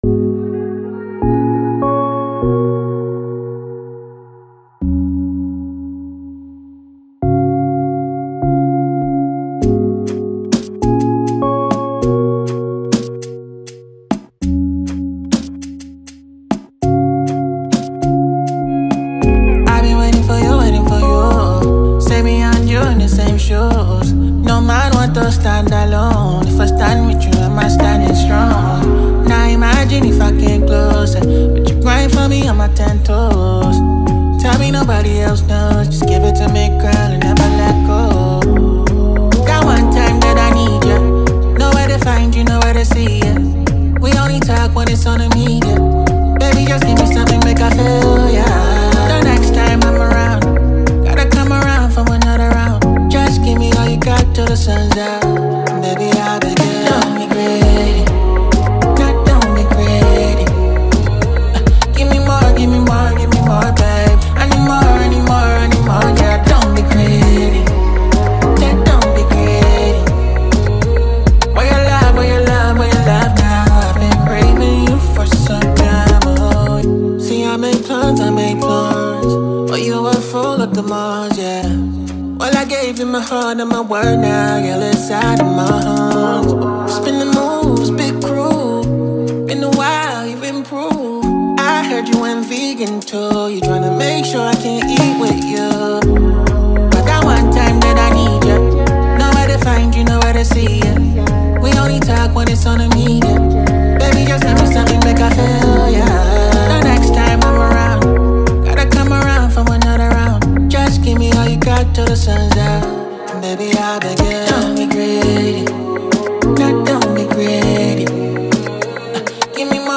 With its infectious melody and heartfelt lyrics